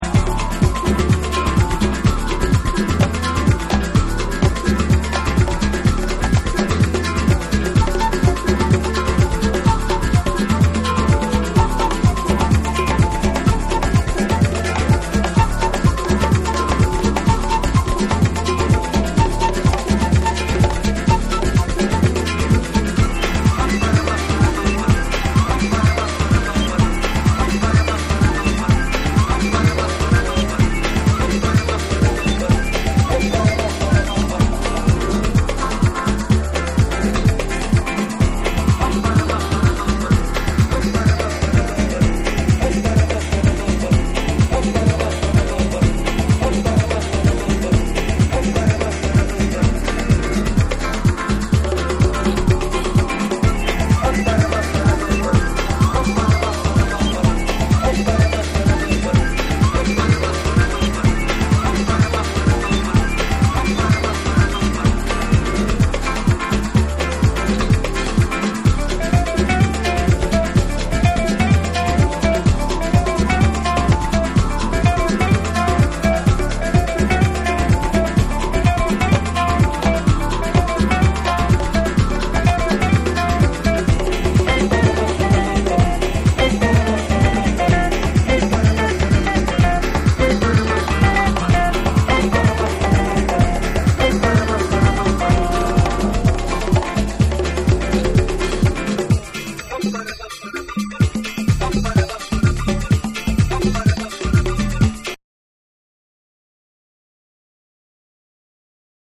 パーカッションを効かせたグルーヴィーなリズムに、アフリカン・ヴォーカルが絡み展開する
TECHNO & HOUSE / ORGANIC GROOVE